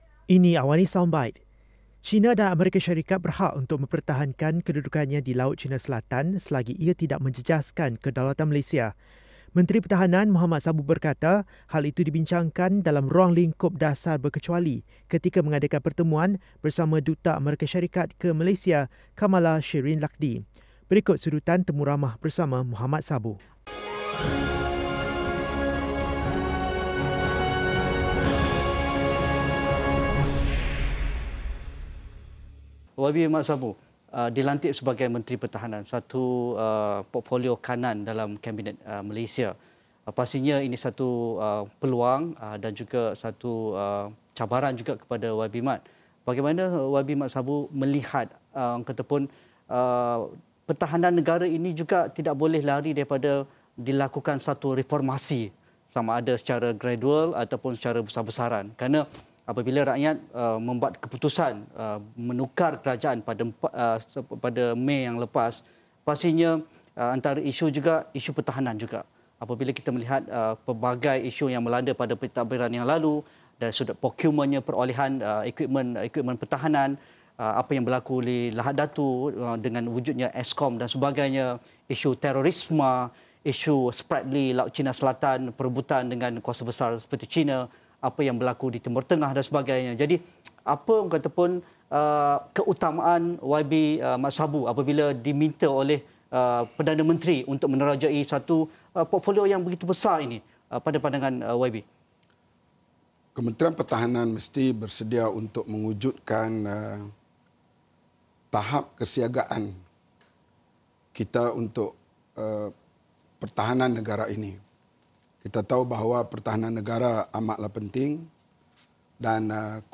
Berikut sedutan temu ramah bersama Mohamad Sabu